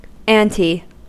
Ääntäminen
IPA : /ˈæːn.tʰi/